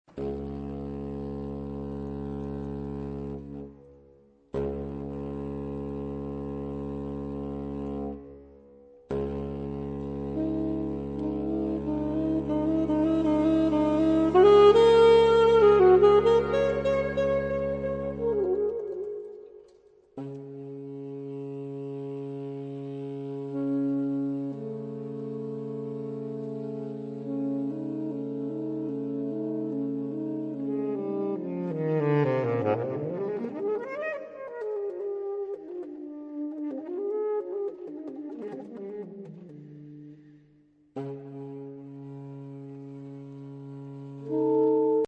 Obsazení: 4 Saxophone (SATBar)
Diese moderne Exkursion mit improvisierten Solos